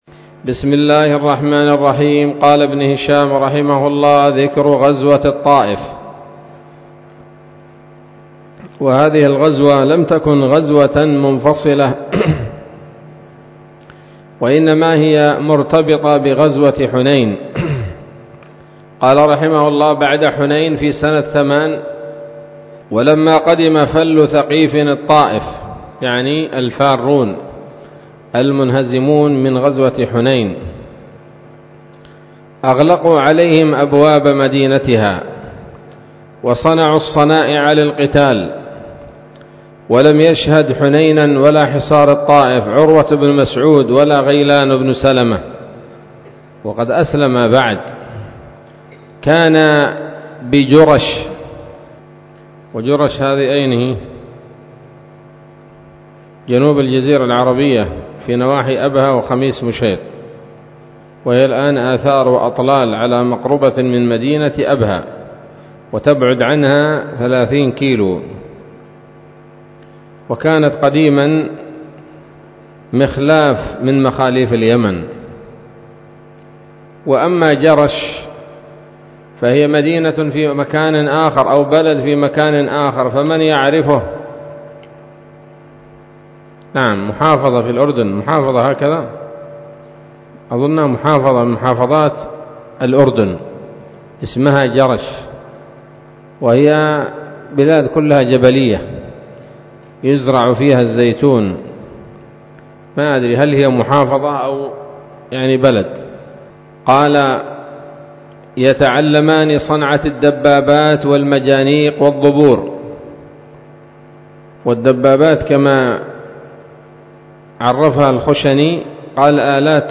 الدرس الثامن والسبعون بعد المائتين من التعليق على كتاب السيرة النبوية لابن هشام